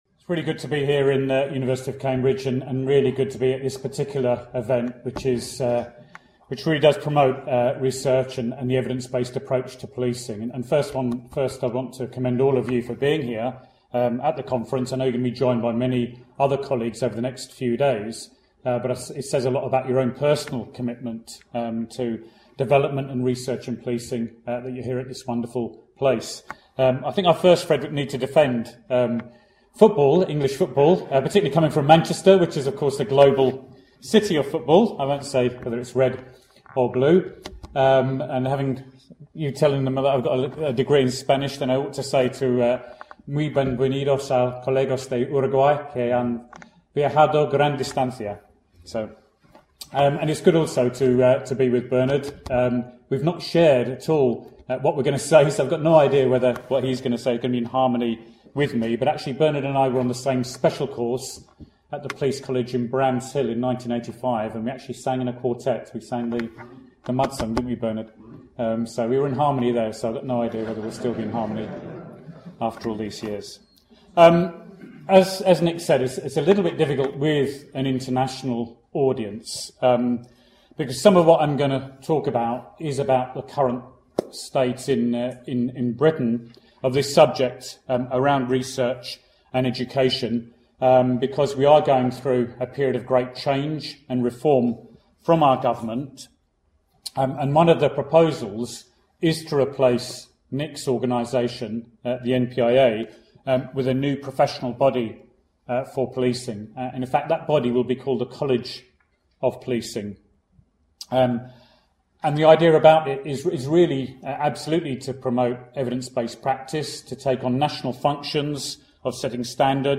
The 5th conference was held at the Sidgwick site at the Faculty of Law, University of Cambridge, on 9-11 July 2012.